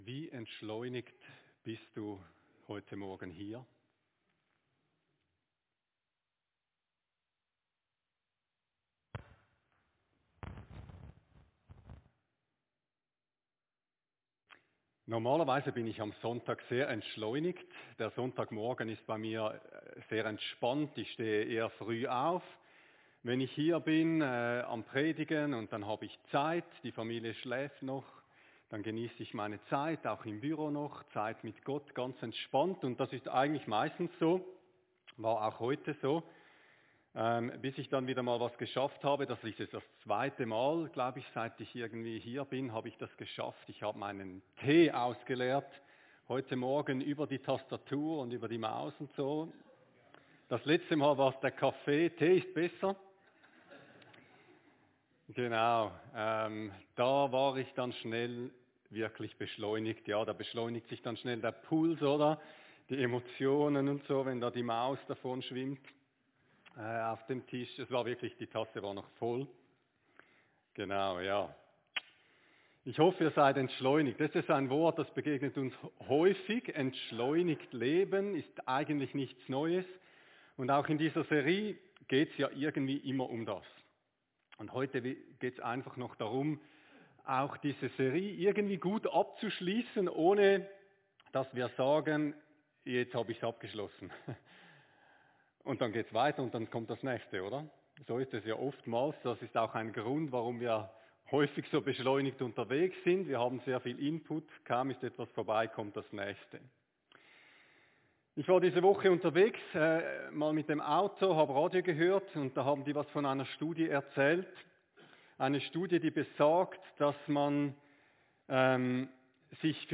Predigt-23.3.25.mp3